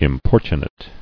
[im·por·tu·nate]